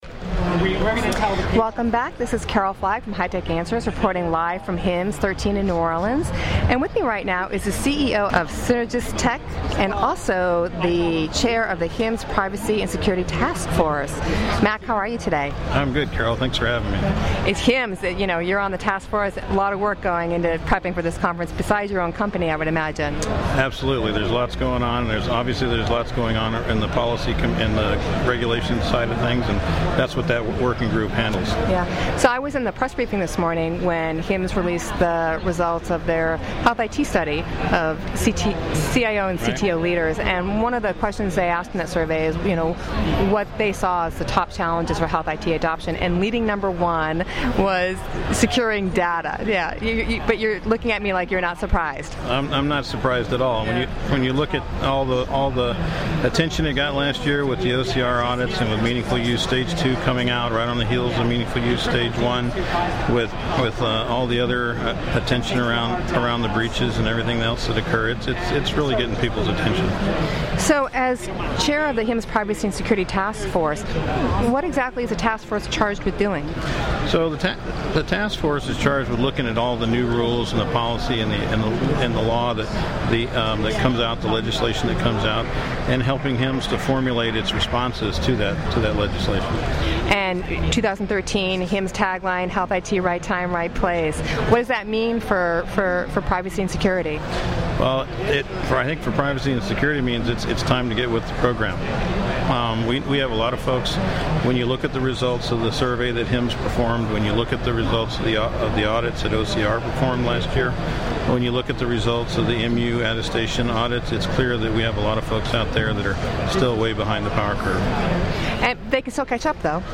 HIMSS highlights are produced by HITECH Answers and represent a series of  podcast interviews of C-level executives from leading health IT companies and agencies.